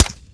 Weapons
Weapon5 (15 kb, .wav)